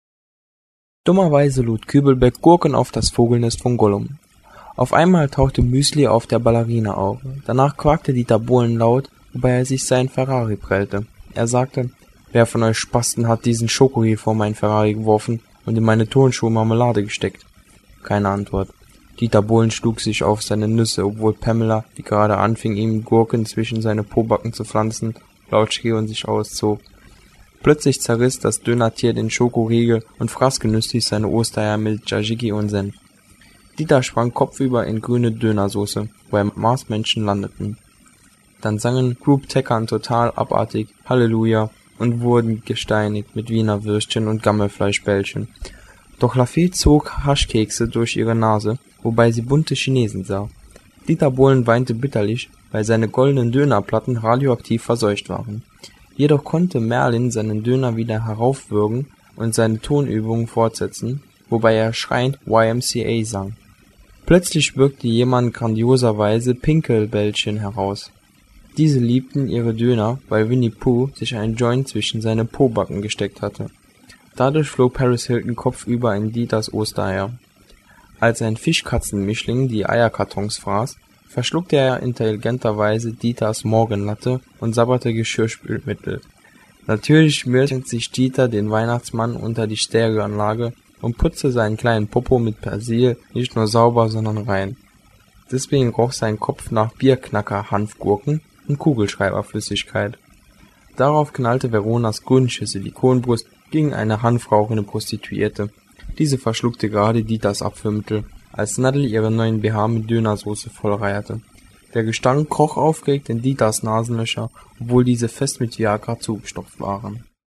Das Hörbuch zur endlosen Story, Seite 6